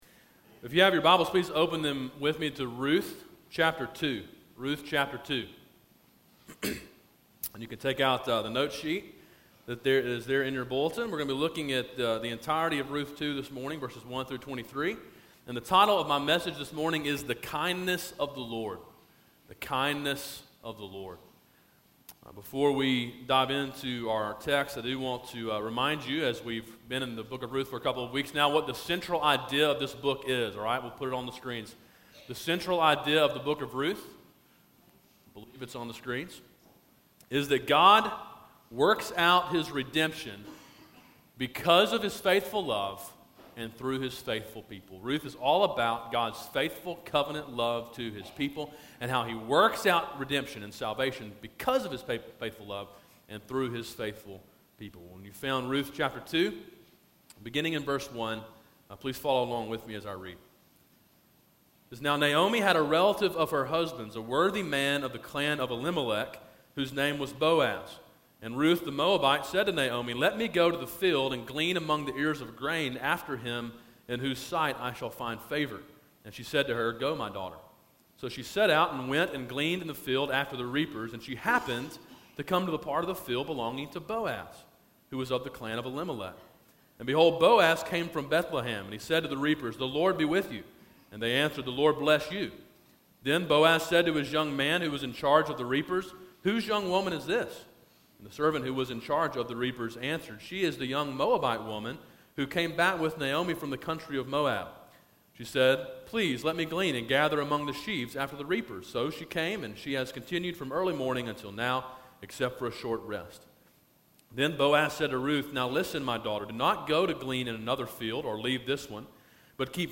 A sermon in a series on the book of Ruth. Preached during the morning service on 2.16.14. Download mp3